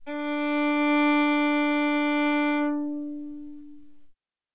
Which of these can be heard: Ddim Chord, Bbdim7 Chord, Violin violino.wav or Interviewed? Violin violino.wav